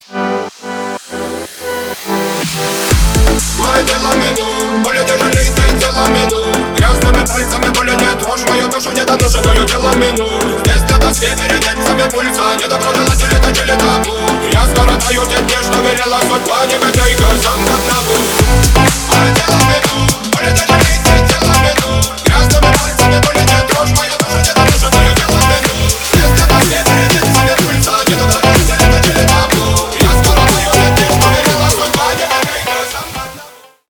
Ремикс
ритмичные